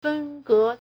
分割 fēngē
fen1ge1.mp3